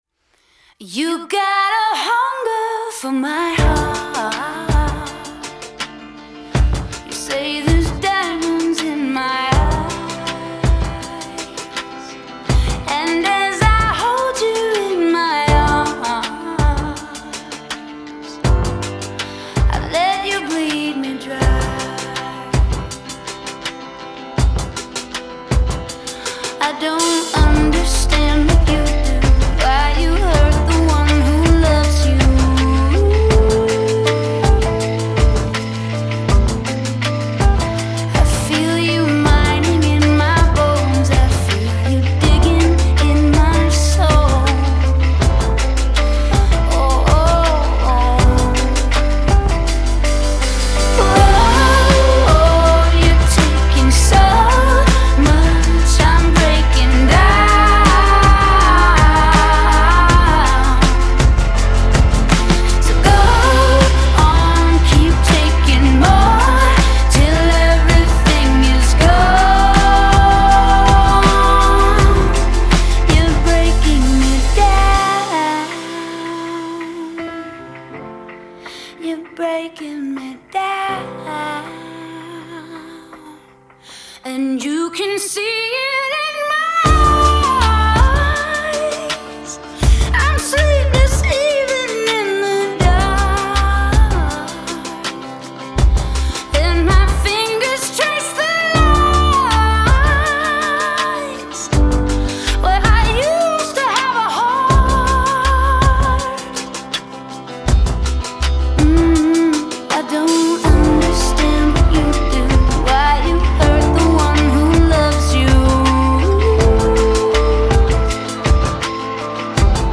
Genre: Pop Rock